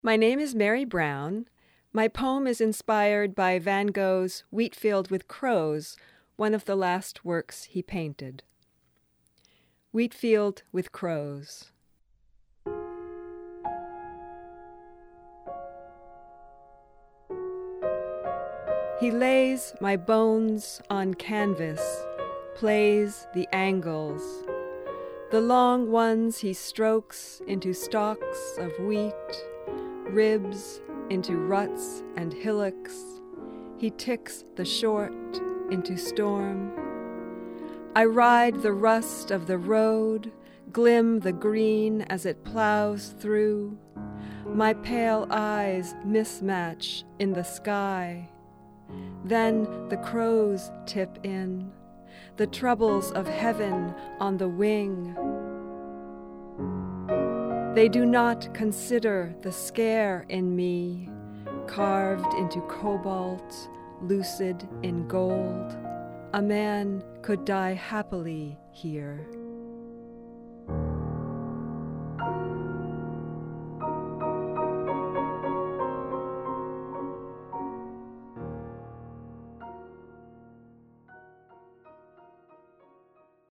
read the poem